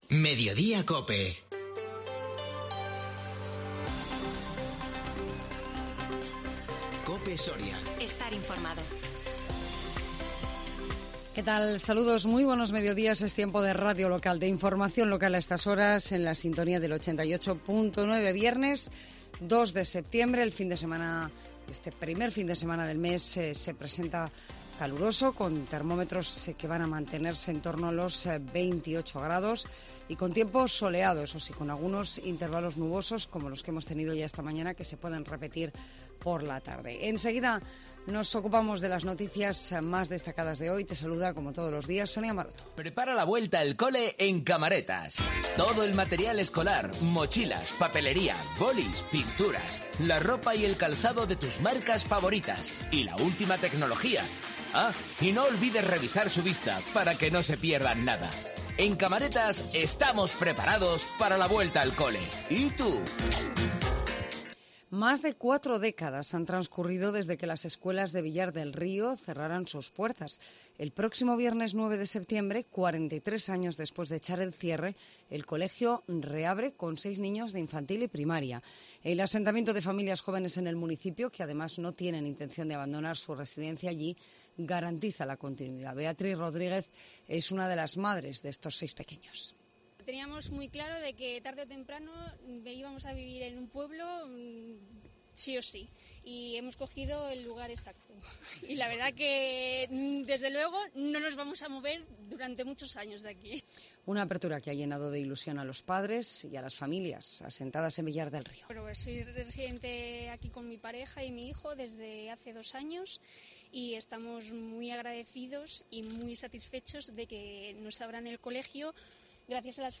INFORMATIVO MEDIODÍA COPE SORIA 2 SEPTIEMBRE 2022